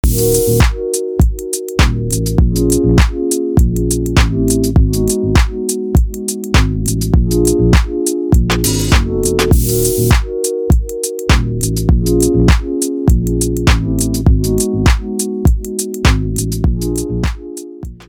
• Качество: 320, Stereo
красивые
dance
Electronic
без слов
Melodic